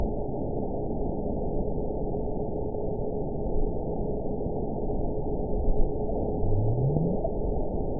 event 920634 date 04/01/24 time 16:06:09 GMT (1 year, 2 months ago) score 9.69 location TSS-AB01 detected by nrw target species NRW annotations +NRW Spectrogram: Frequency (kHz) vs. Time (s) audio not available .wav